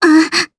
Gremory-Vox_Damage_jp_02.wav